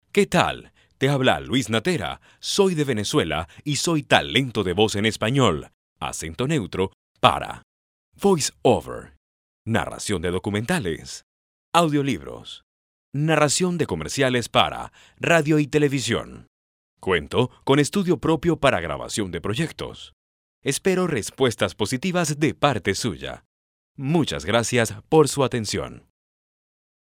I have Spanish Neutral accent. I have my own studio with professional equipments, for record my proyects.
Sprechprobe: Industrie (Muttersprache):